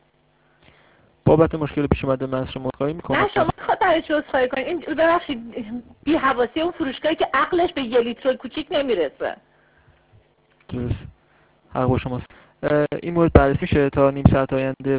angerpart8.wav